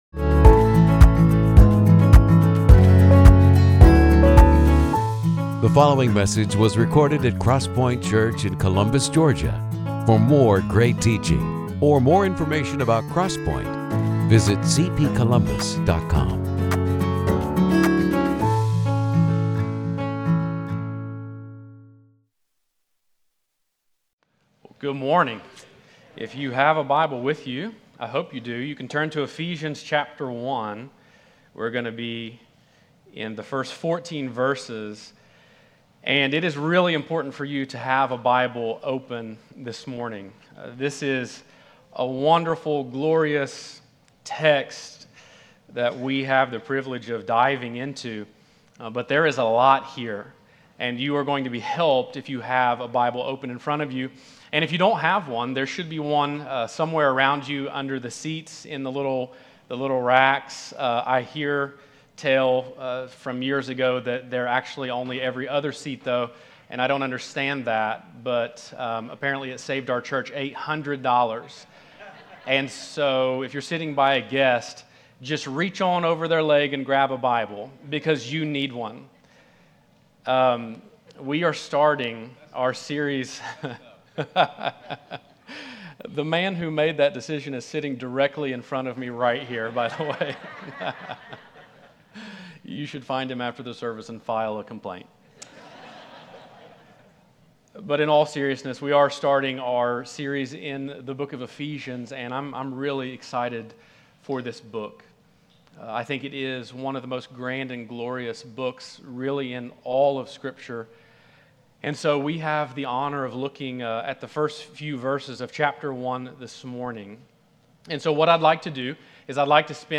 The sermons of CrossPointe Church in Columbus, Ga.